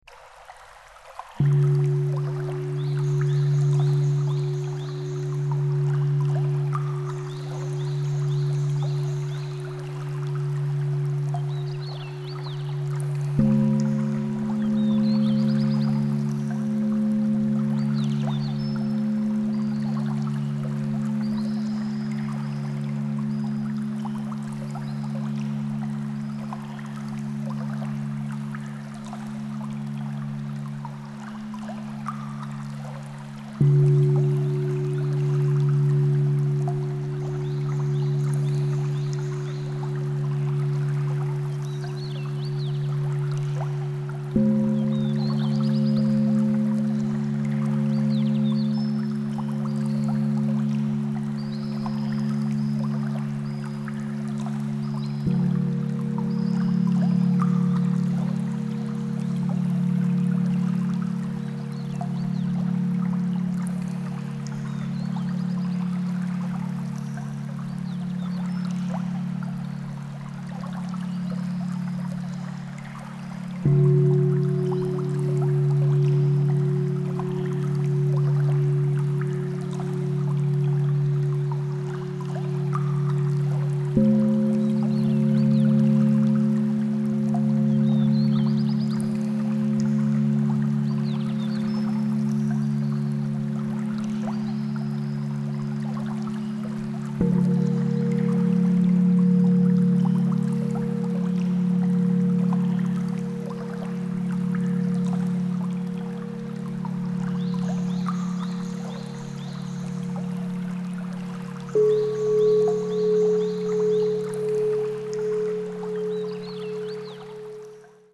リラックスや癒しを目的とした背景音楽。自然の音や癒しの楽器の音を組み合わせ、心地よい雰囲気を提供します。